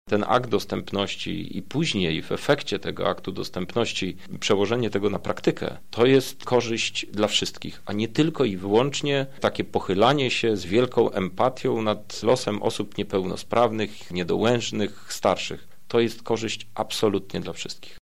To ważna regulacja, która może przyczynić się do poprawy sytuacji około 80-ciu milionów obywateli Unii Europejskiej – uważa europoseł PSL, Krzysztof Hetman.